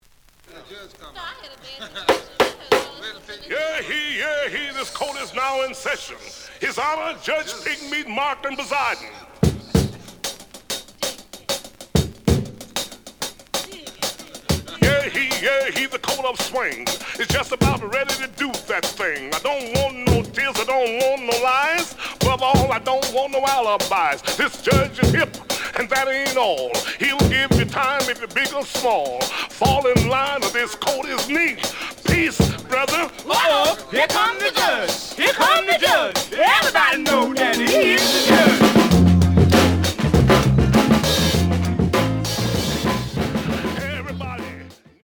The audio sample is recorded from the actual item.
●Genre: Funk, 60's Funk
Edge warp.